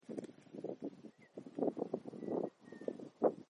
Perdiz (Rhynchotus rufescens)
Nome em Inglês: Red-winged Tinamou
Fase da vida: Adulto
Detalhada localização: Campo de espinal a 15 km al sur de la ruta 35
Condição: Selvagem
Certeza: Gravado Vocal